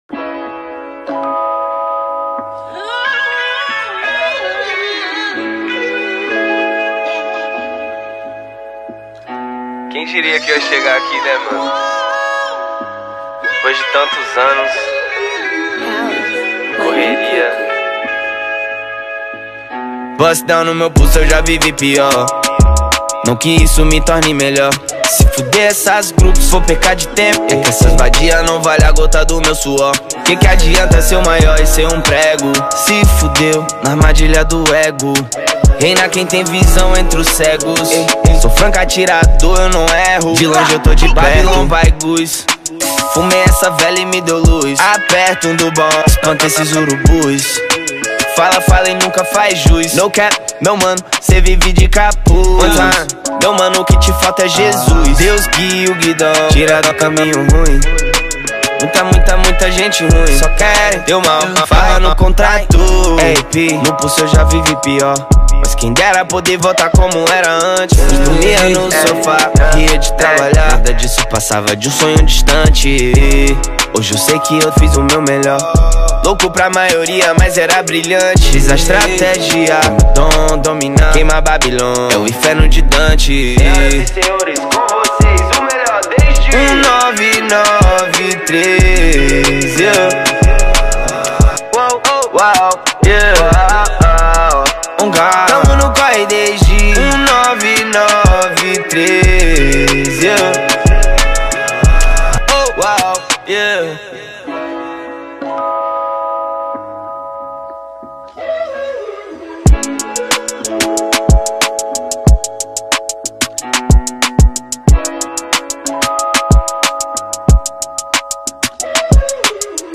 2024-10-16 19:34:18 Gênero: Trap Views